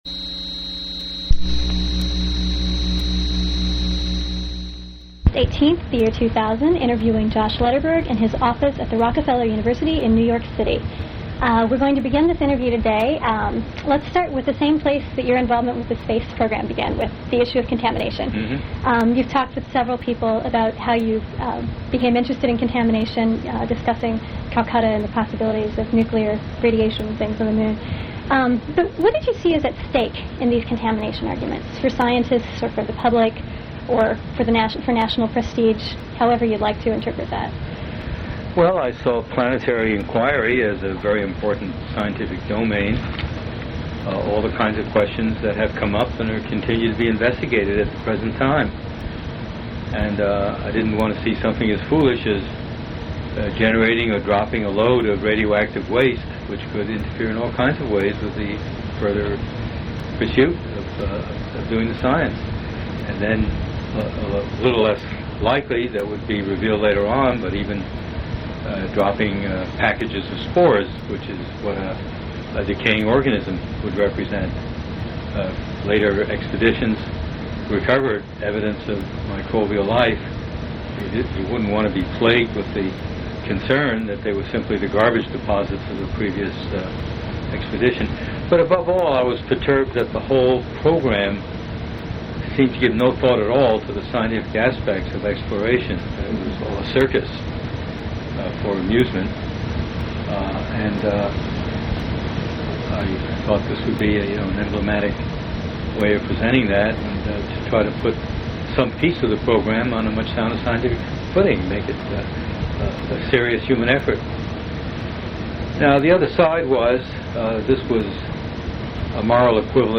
Oral history interview with Joshua Lederberg